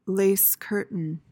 PRONUNCIATION:
(LAYS-kuhr-tuhn)